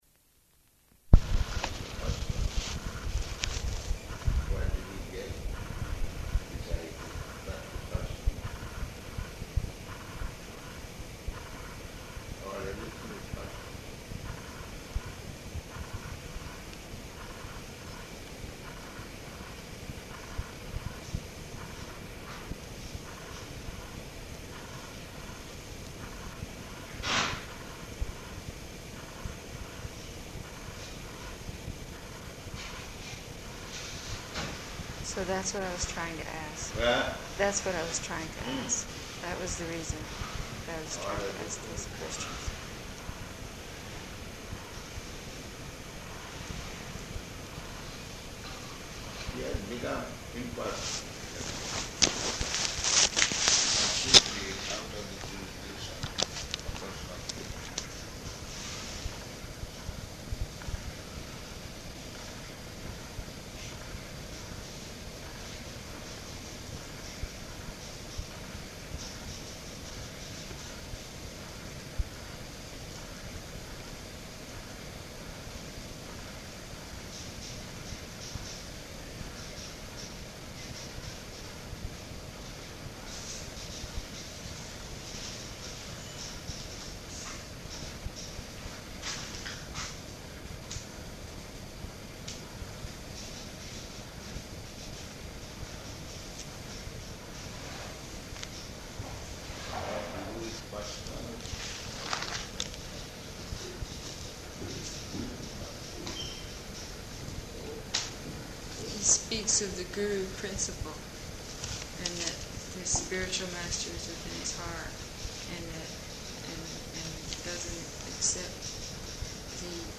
Room Conversation
Location: Honolulu